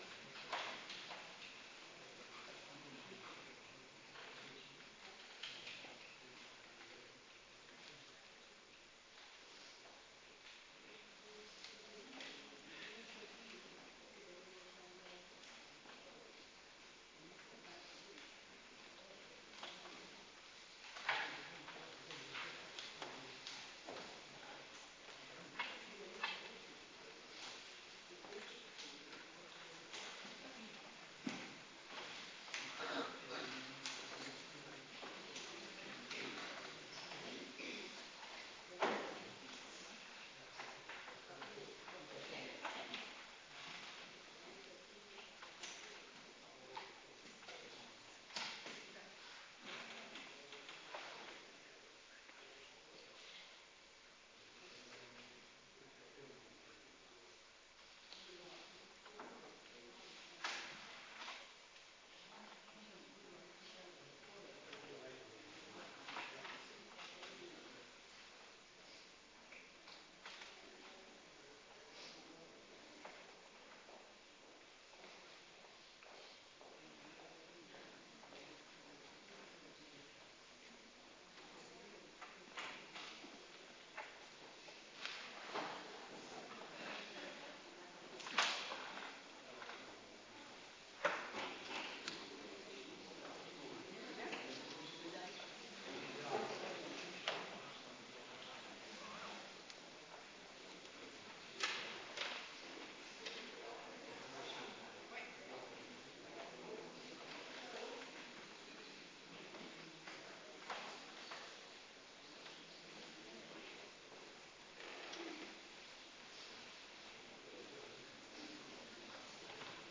Kerkdiensten
VIERING HEILIG AVONDMAAL